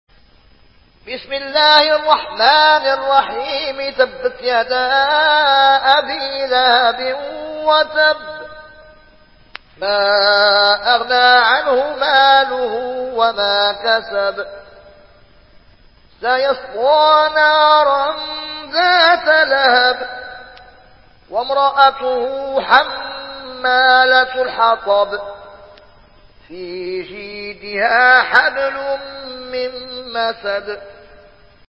روایت ورش